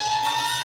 SERVO SE10.wav